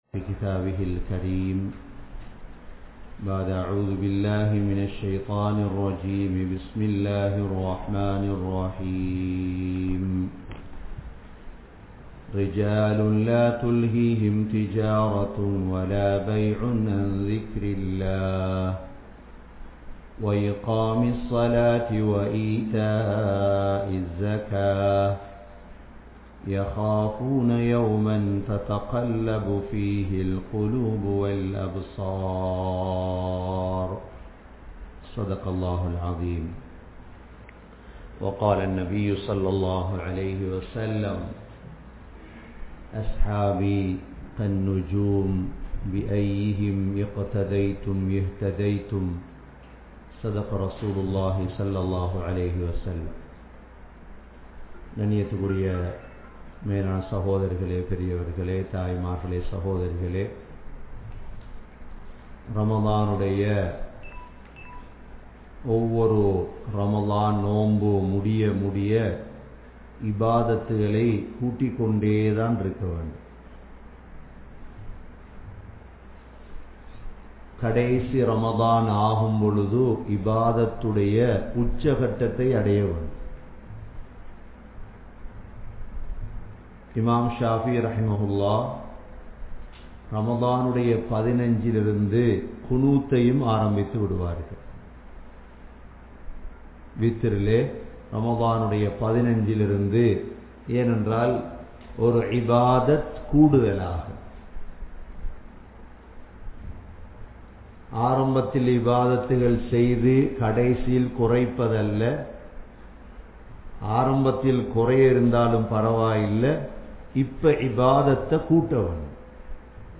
Abdul Rahman Ibn Auf(Rali) | Audio Bayans | All Ceylon Muslim Youth Community | Addalaichenai
Canada, Toronto, Thaqwa Masjidh